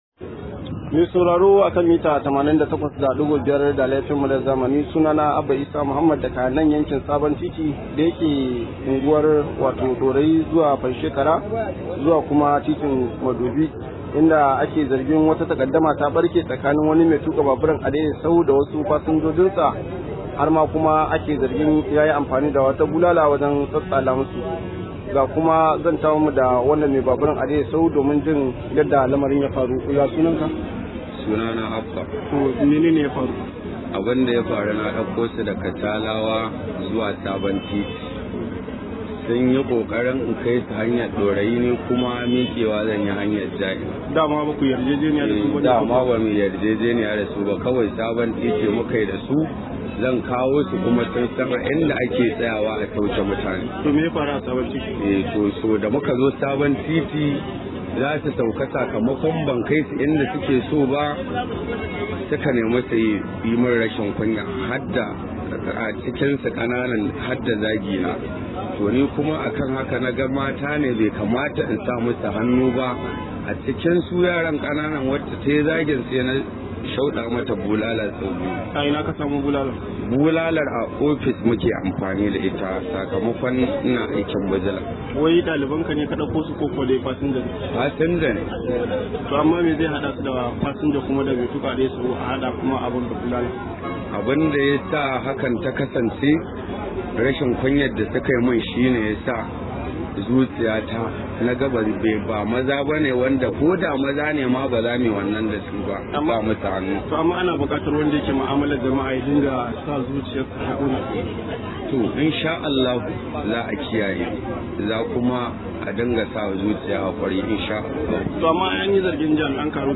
Rahoto: Ɗan Adadaita ya tsala wa fasinjar sa bulala